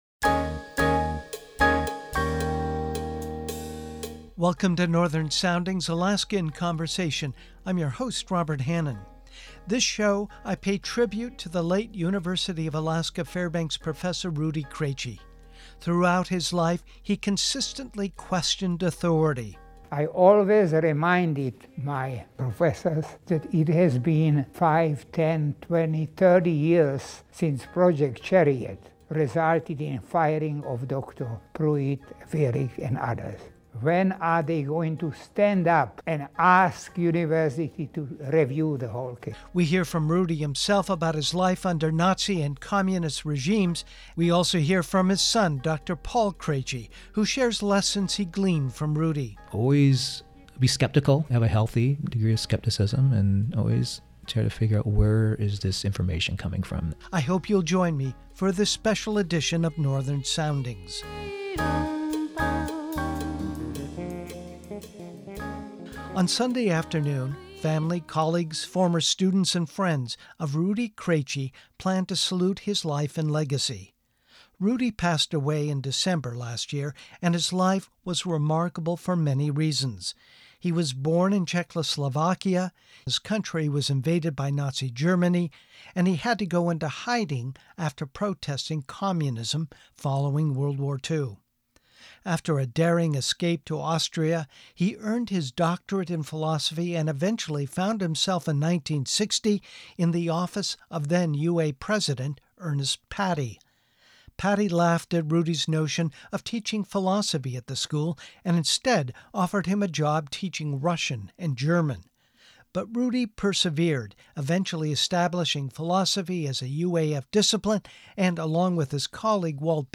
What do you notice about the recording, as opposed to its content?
Part of today’s show is taken from that discussion.